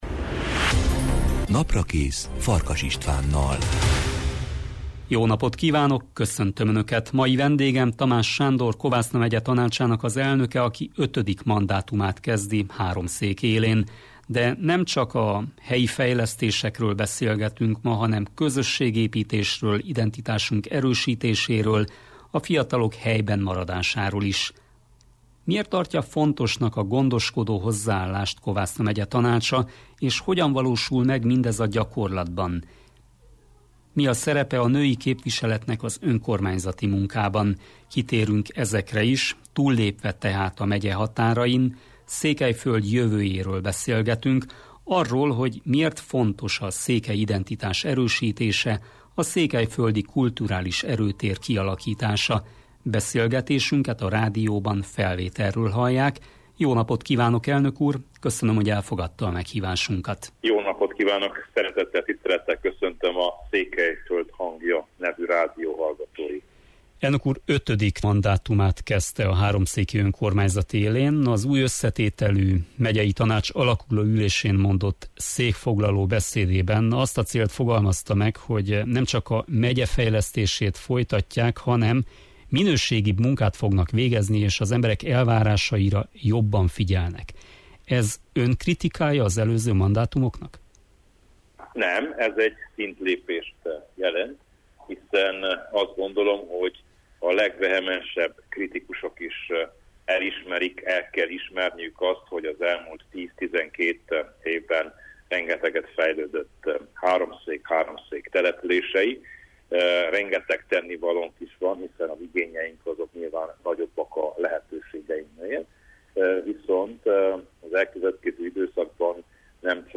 A mai Naprakész vendége Tamás Sándor, aki ötödik mandátumát kezdi a Kovászna megyei tanács élén. De nemcsak a háromszéki fejlesztésekről, hanem a közösségépítésről, identitásunk erősítéséről, és a fiatalok helyben maradásáról is beszélgetünk.